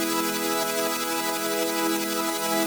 SaS_MovingPad01_90-E.wav